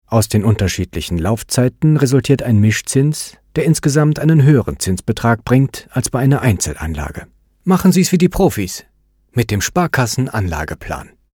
Er trägt mit seiner freundlichen Stimme dazu bei, dass Ihr Produkt, Ihr Projekt an Bedeutung gewinnt und einzigartig wird, ganz nach Ihren Wünschen.
Sprechprobe: eLearning (Muttersprache):